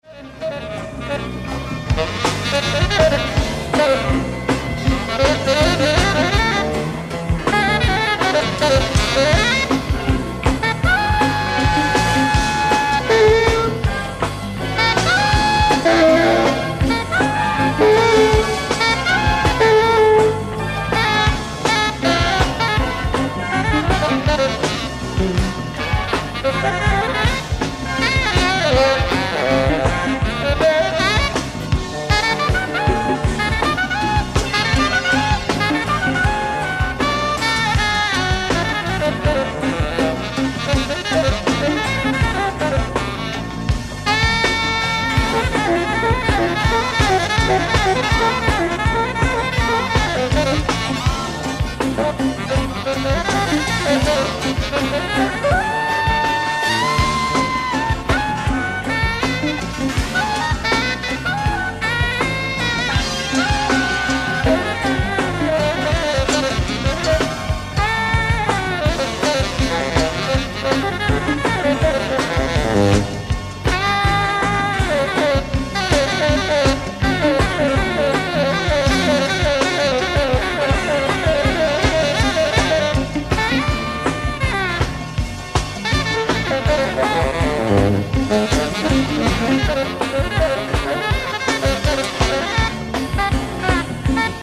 NEVER RELEASED BRFORE STEREO RECORDING AND BEST QUALITY EVER
FULL SOUNDBOARD RECORDING